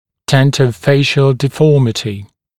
[ˌdentə(u)’feɪʃ(ə)l][ˌдэнто(у)’фэйш(э)л]челюстно-лицевая деформация